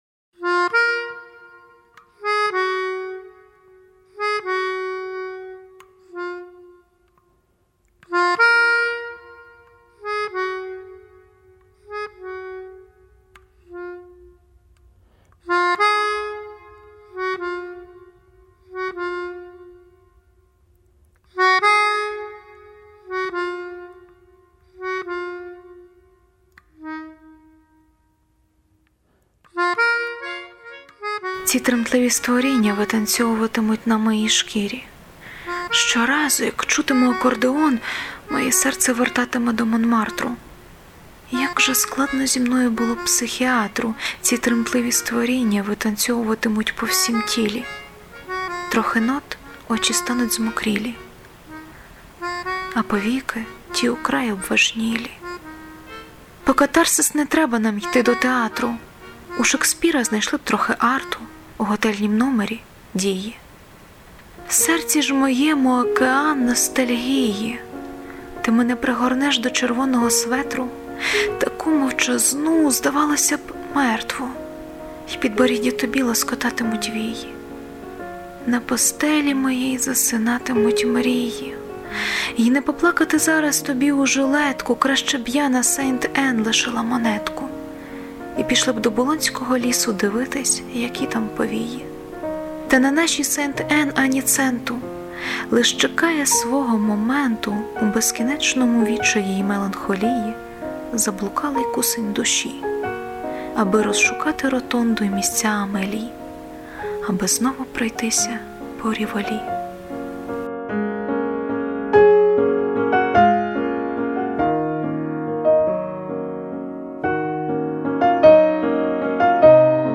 Рубрика: Поезія, Лірика
Дуже,дуже! 12 Акомпонімент в 10! 16 give_rose